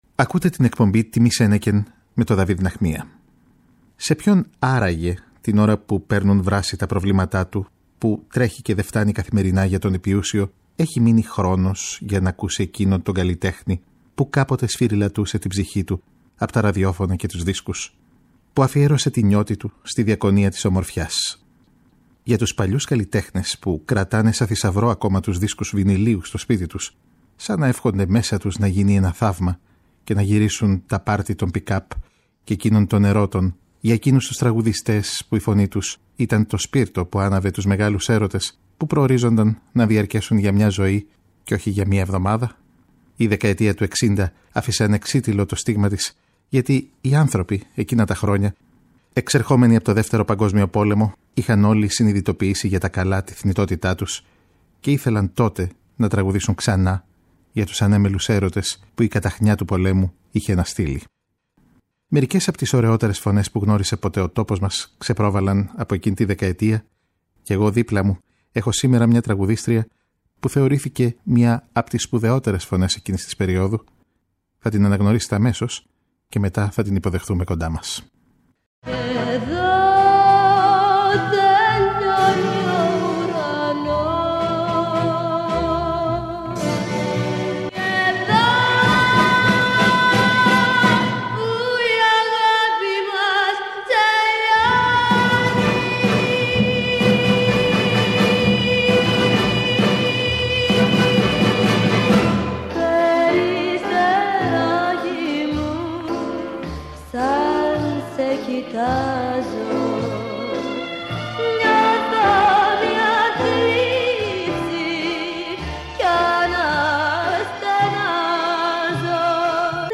Η εκπομπή παρουσιάζει μια μεγάλη φωνή της δεκαετίας του 1960,τη Ζωή Κουρούκλη, σε μία εκ βαθέων αφήγηση της ζωής και του παρασκηνίου της καριέρας της. Μέσα απ’ τον ήχο των υπέροχων τραγουδιών που ερμήνευσε, η αγαπημένη τραγουδίστρια του Μίμη Πλέσσα, του Κώστα Καπνίση και του Μάνου Χατζιδάκι, ανοίγει την καρδιά της και για πρώτη φορά αποκαλύπτει το ψηφιδωτό των μικρών ιδιωτικών δραμάτων που στο άνθος της ηλικίας της την κράτησαν σε απόσταση από το χρυσό σενάριο της διεθνούς καριέρας.